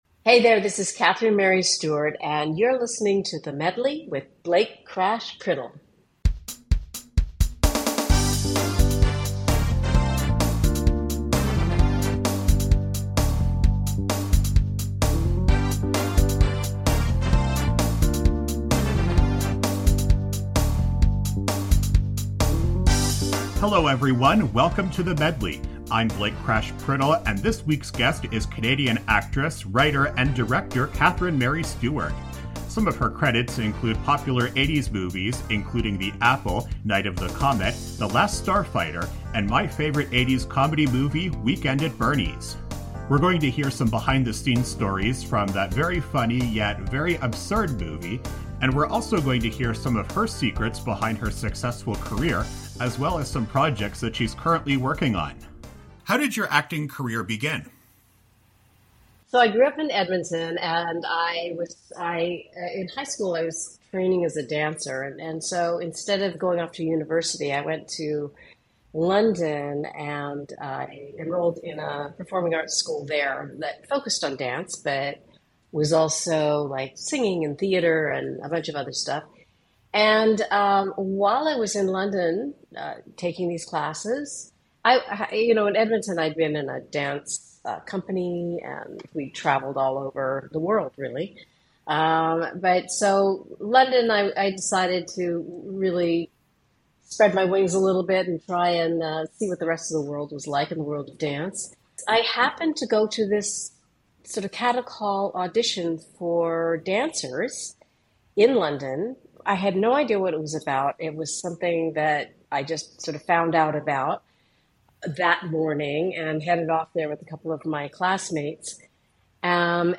This week, Canadian Actress Catherine Mary Stewart of Weekend at Bernie's fame tells us about her experience in the industry.